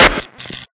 Transceiver.ogg